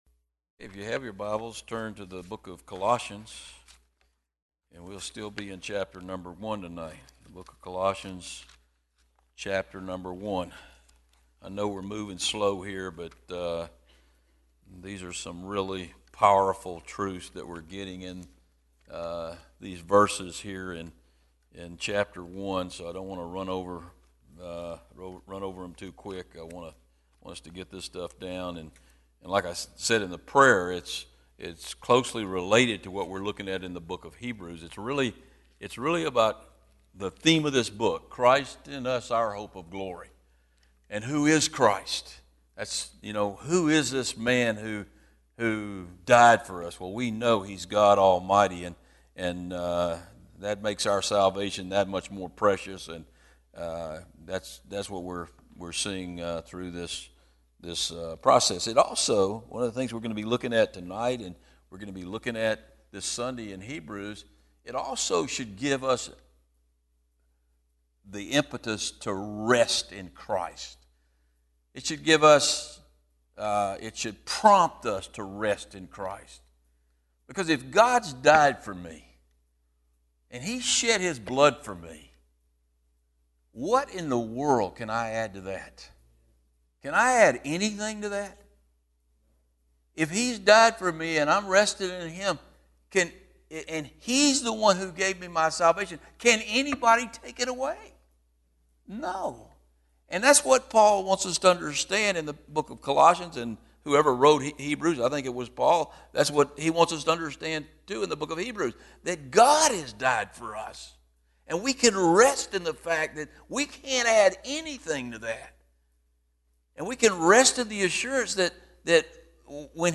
These teachings on Colossians are from Wednesday evening service.